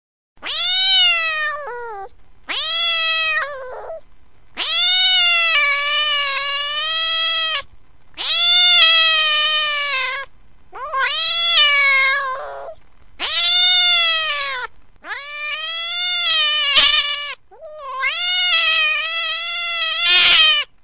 Другие рингтоны по запросу: | Теги: кот, кошка